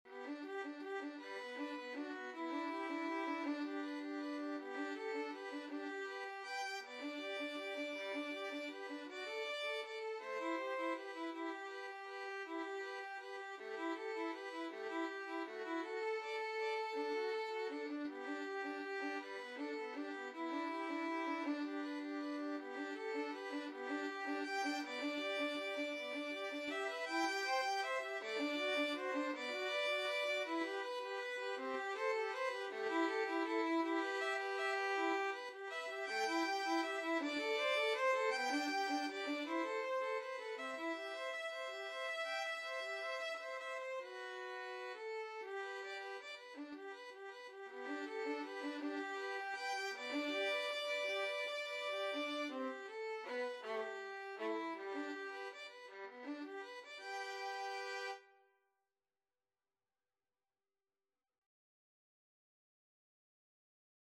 G major (Sounding Pitch) (View more G major Music for Violin Duet )
6/8 (View more 6/8 Music)
Andante ingueno
Violin Duet  (View more Intermediate Violin Duet Music)
Classical (View more Classical Violin Duet Music)